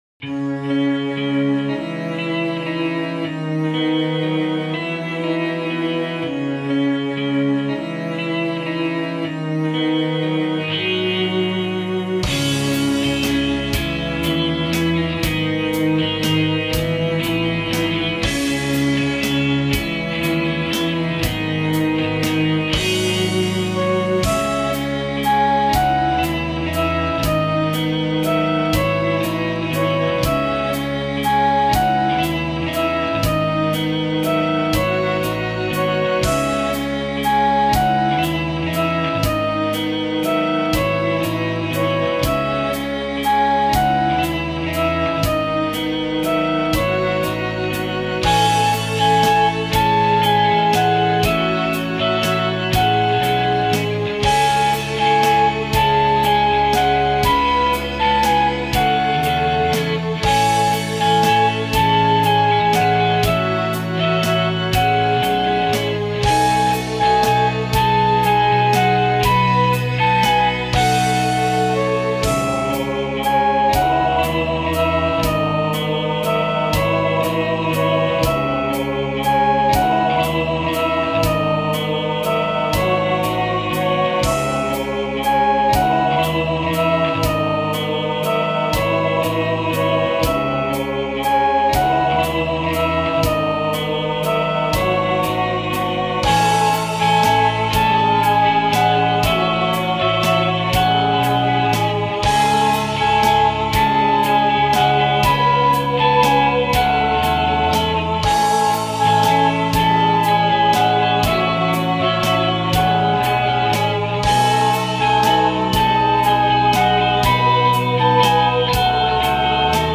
そんな切ない物語を三拍子の旋律で描いた曲です。